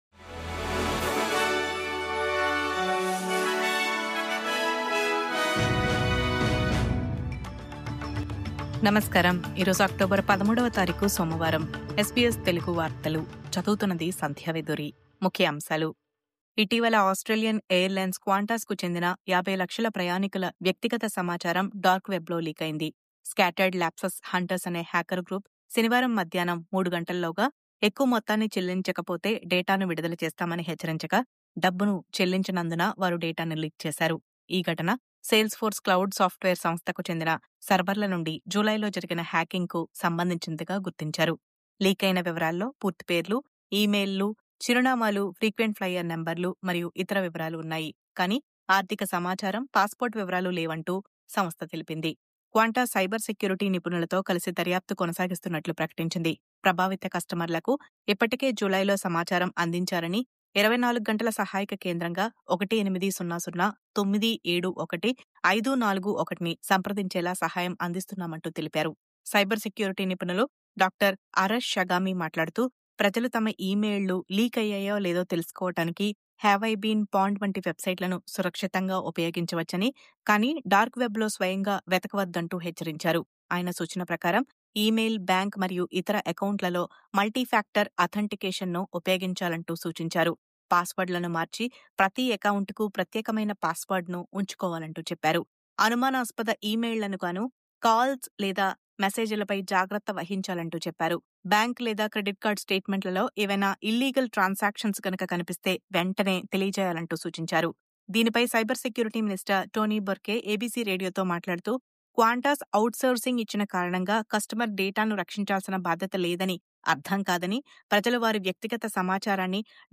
News update: 50 లక్షల ప్రయాణికుల సమాచారం “డార్క్ వెబ్”‌లో లీక్… భారీ మొత్తాన్ని డిమాండ్ చేసిన హ్యాకర్‌లు..